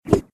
poleSwing.ogg